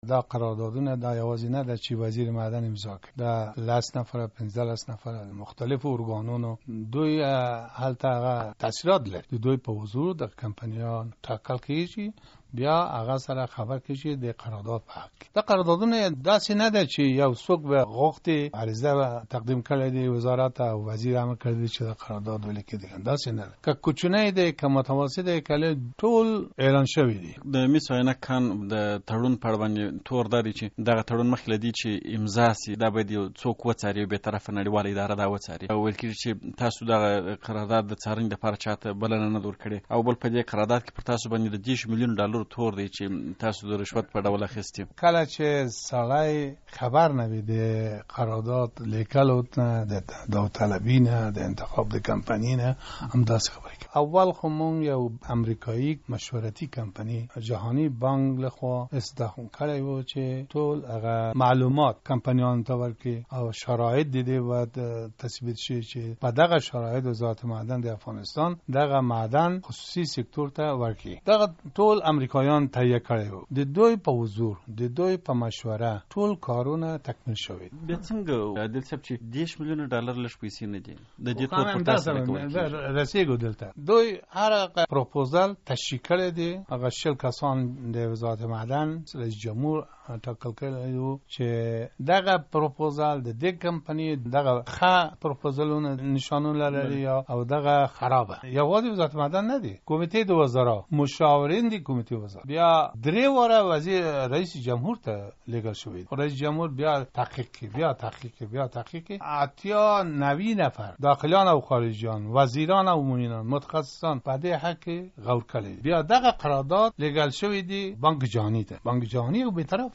له محمد ابراهیم عادل سره مرکه